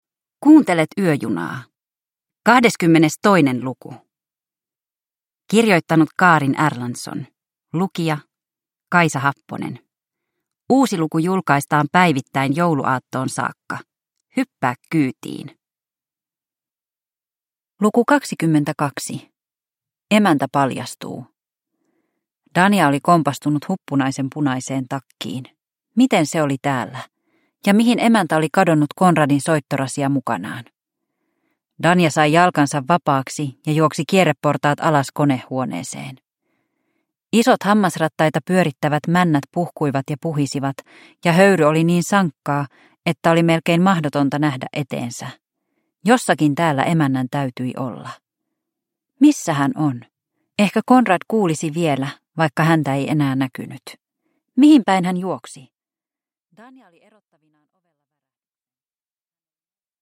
Yöjuna luku 22 – Ljudbok